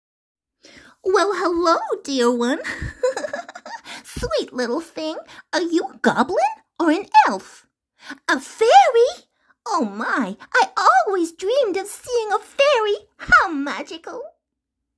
Cartoon Girl Fairytale